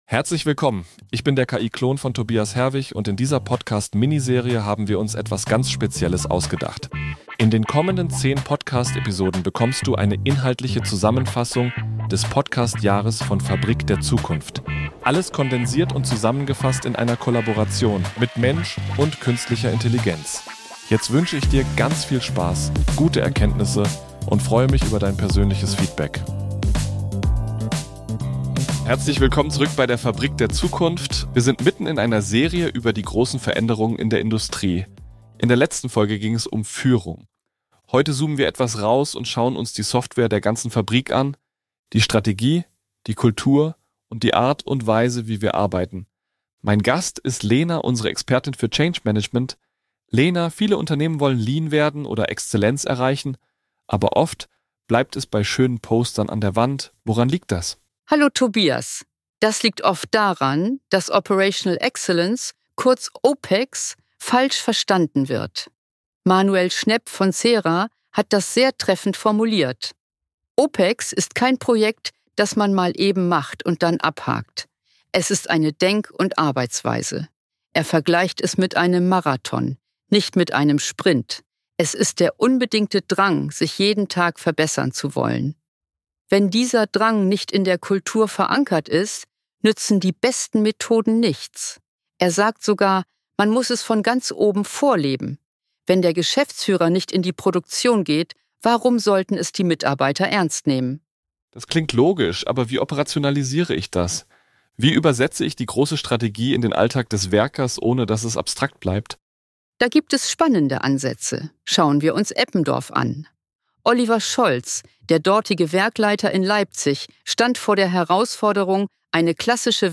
Expertin für Change & Strategie, spricht der KI‑Klon